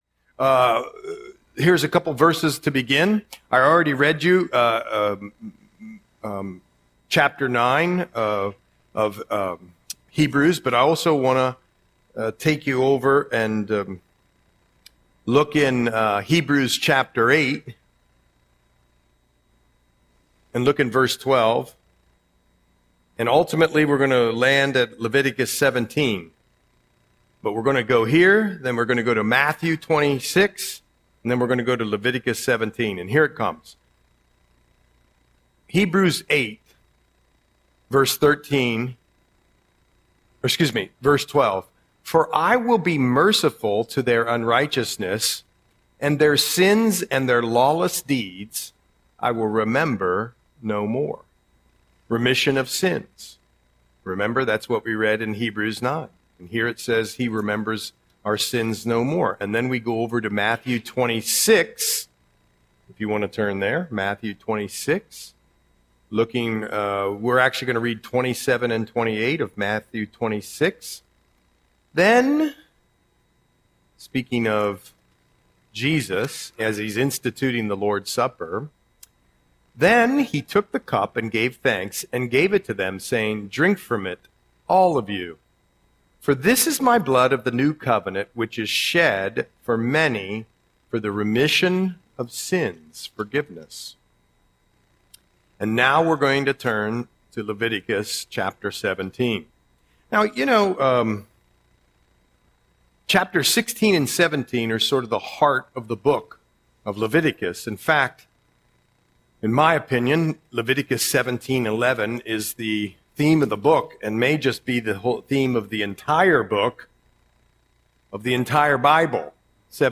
Audio Sermon - November 19, 2025